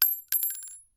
Bullet Shell Sounds
rifle_generic_2.ogg